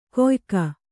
♪ koyka